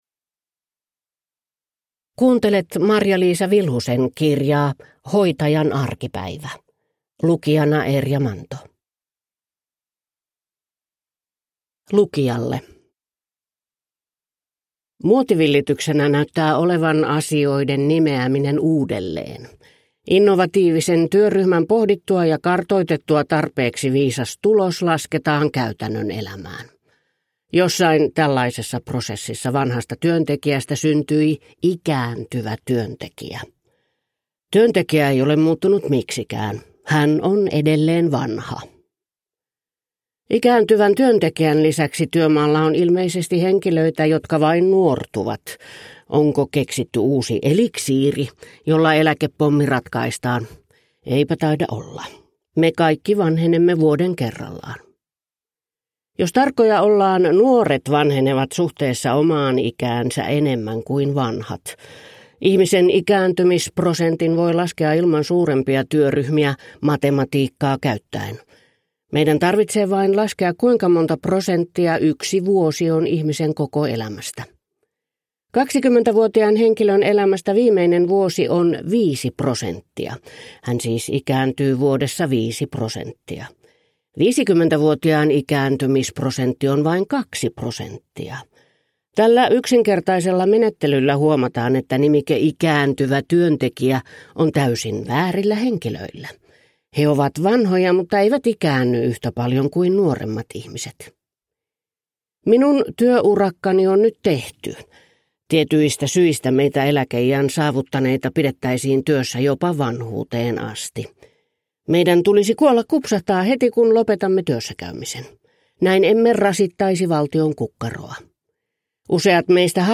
Hoitajan arkipäivä (ljudbok) av Marja-Liisa Vilhunen